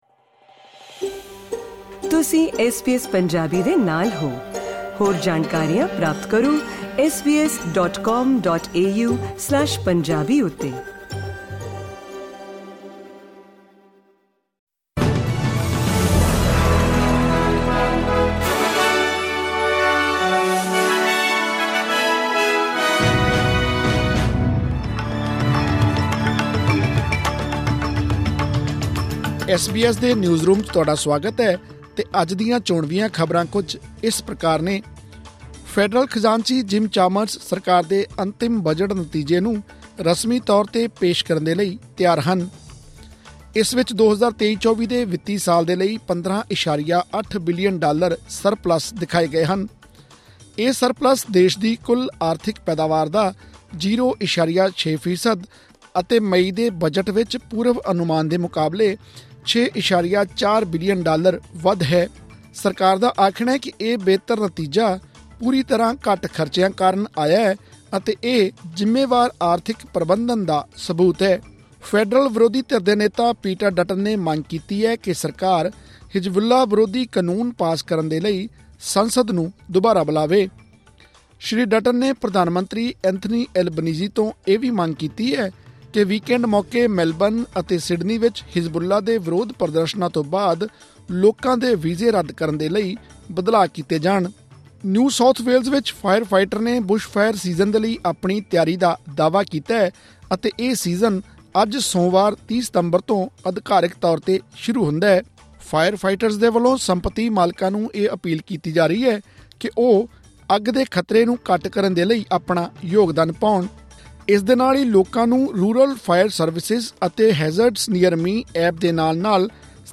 ਐਸ ਬੀ ਐਸ ਪੰਜਾਬੀ ਤੋਂ ਆਸਟ੍ਰੇਲੀਆ ਦੀਆਂ ਮੁੱਖ ਖ਼ਬਰਾਂ: 30 ਸਤੰਬਰ 2024